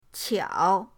qiao3.mp3